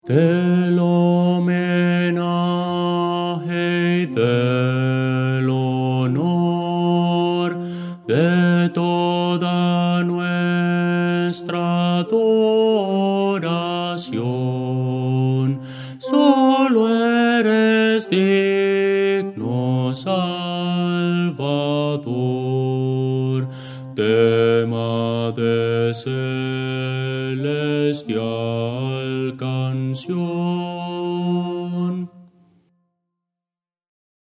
Voces para coro
Bajo – Descargar
Audio: MIDI